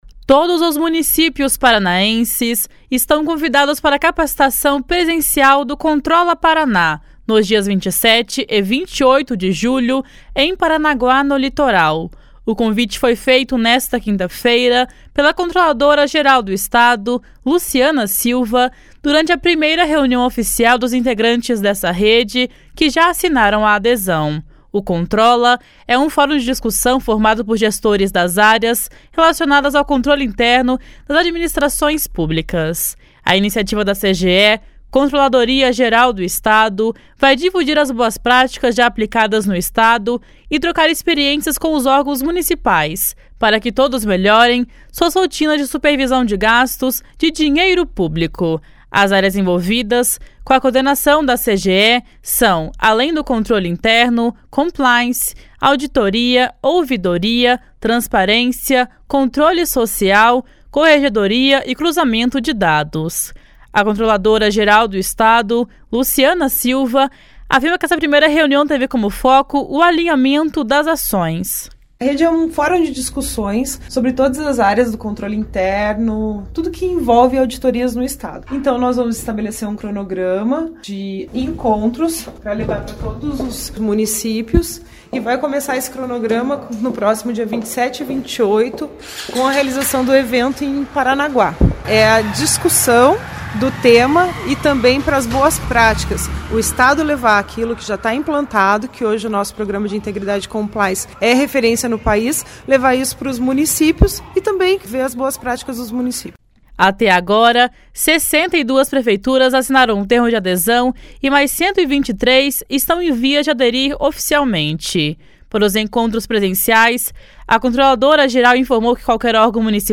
A controladora-geral do Estado, Luciana Silva, afirma que essa primeira reunião teve como foco o alinhamento das ações. // SONORA LUCIANA SILVA //